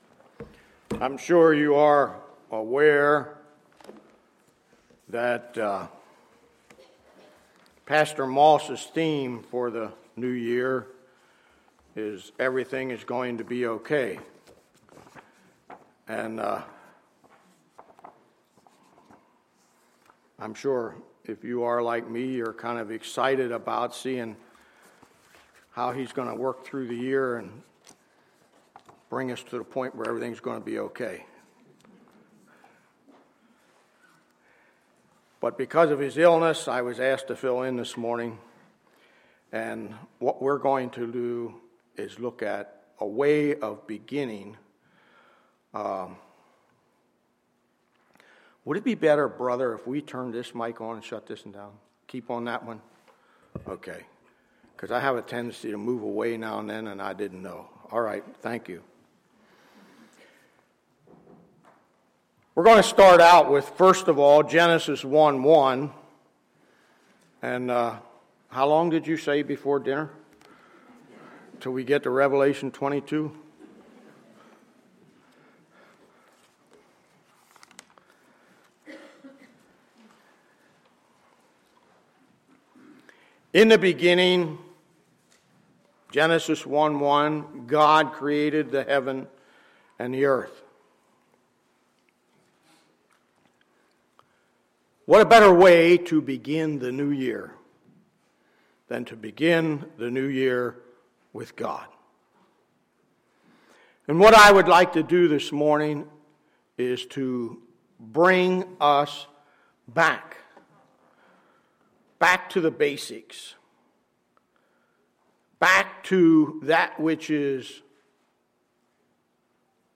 Sunday, January 4, 2015 – Sunday Morning Service